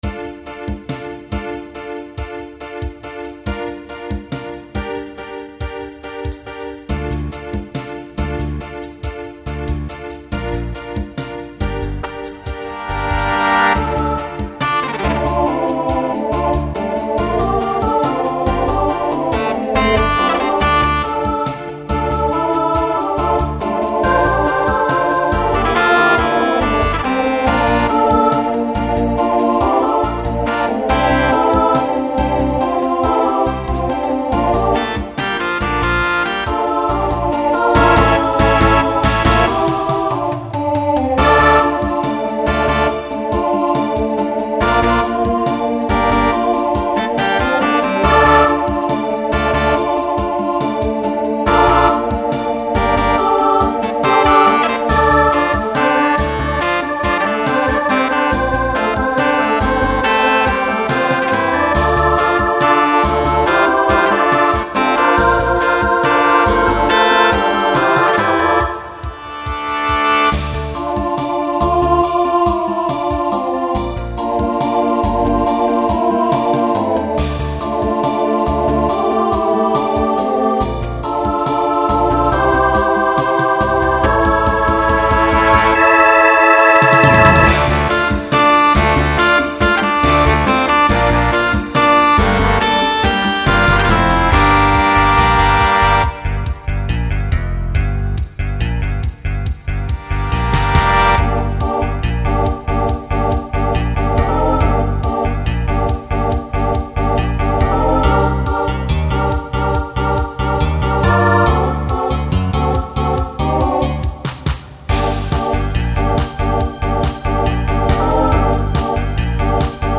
Genre Pop/Dance , Rock
Voicing SSA